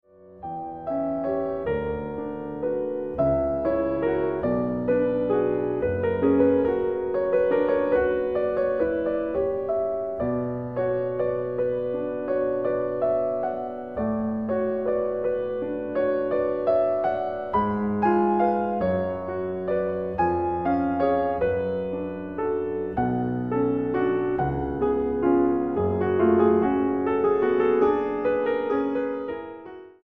pianista.